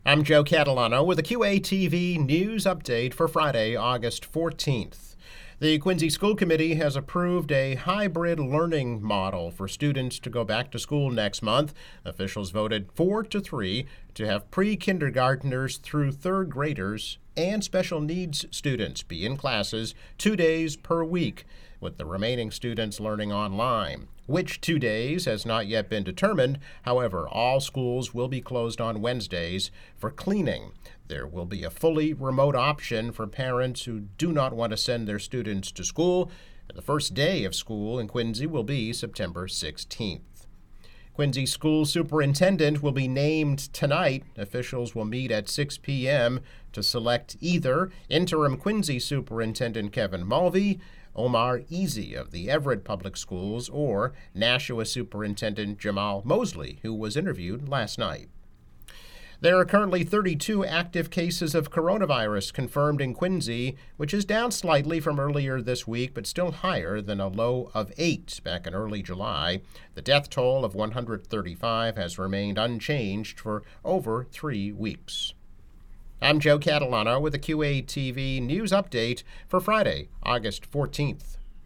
News Update - August 14, 2020